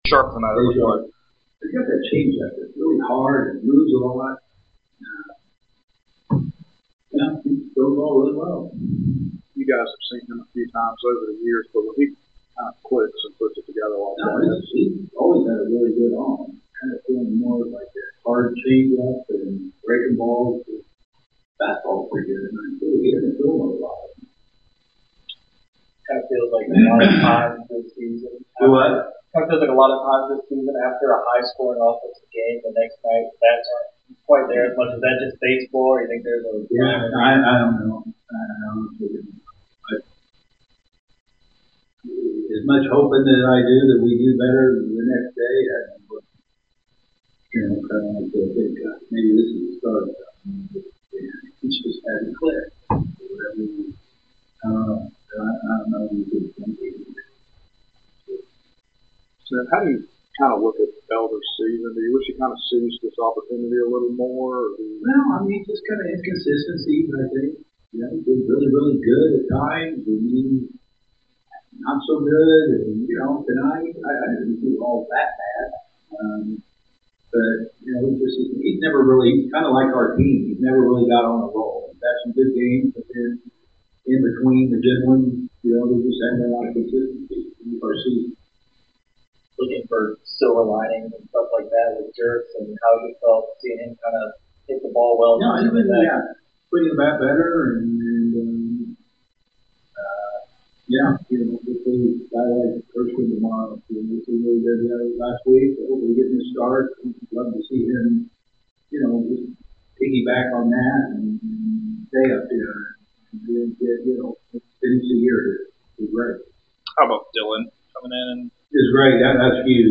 Atlanta Braves Manager Brian Snitker Postgame Interview after losing to the Miami Marlins at Truist Park.